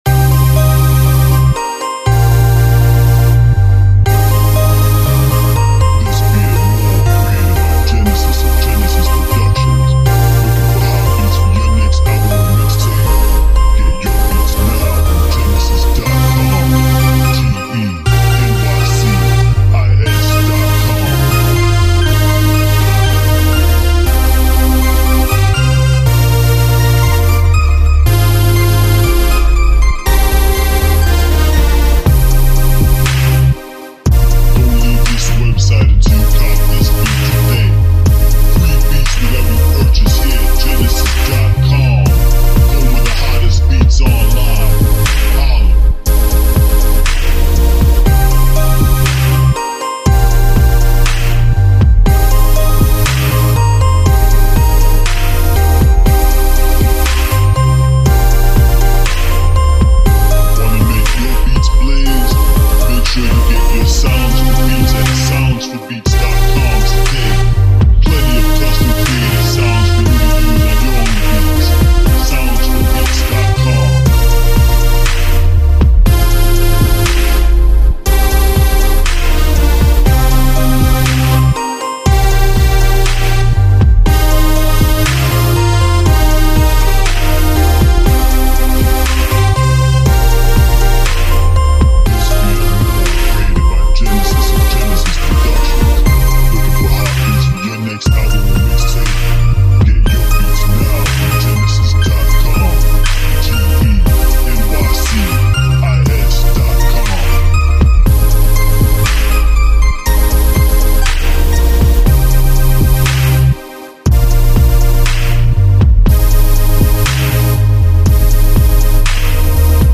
R&B Instrumentals